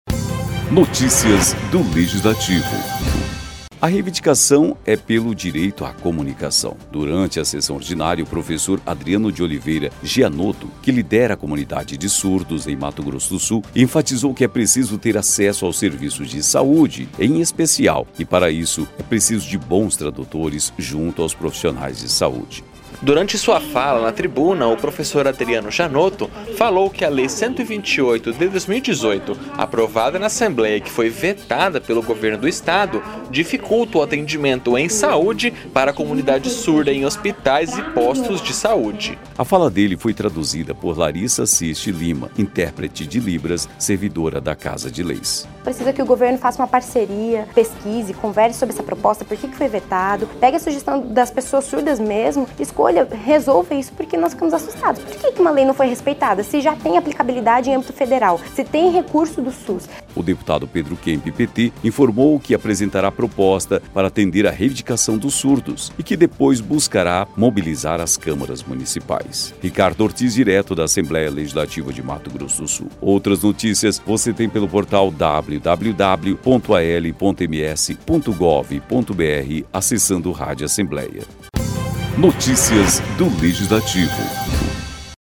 O deputado Pedro Kemp (PT) informou que apresentará proposta para atender à reivindicação dos surdos e que depois buscará mobilizar as Câmaras Municipais.